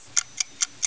tick.wav